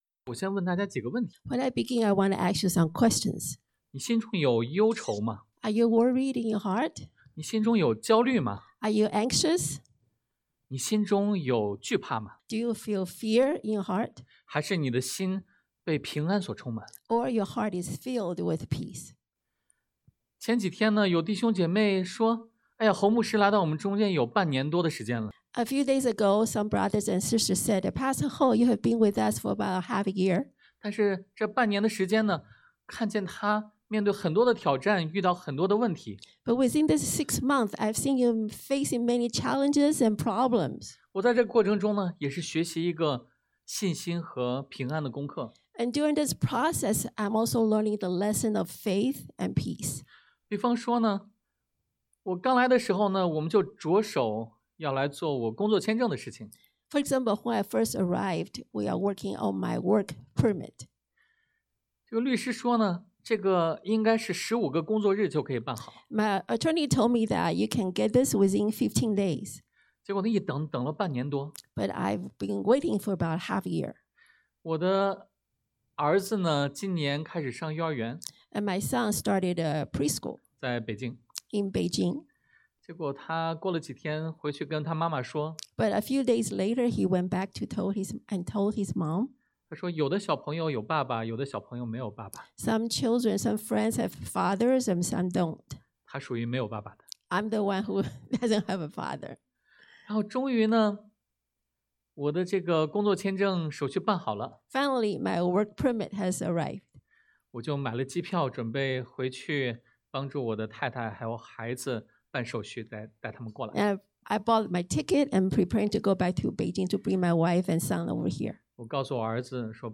Passage: 约翰福音 John 14:1-14 Service Type: Sunday AM